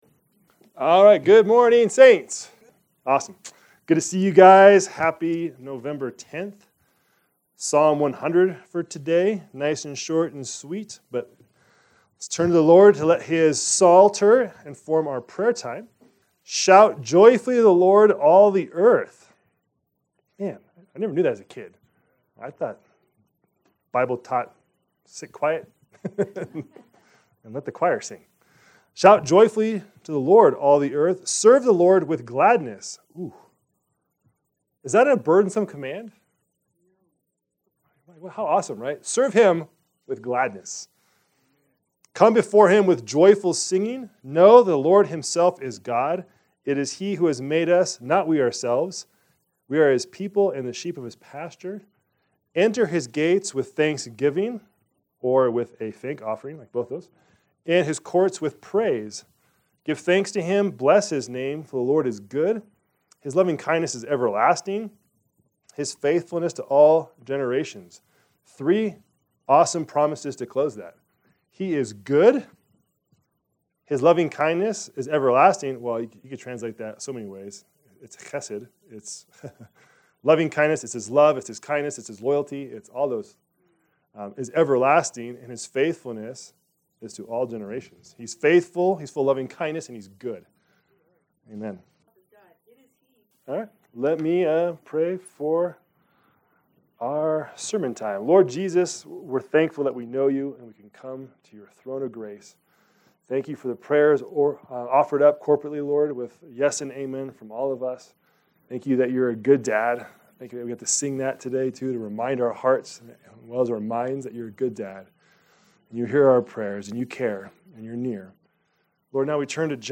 Our Sermons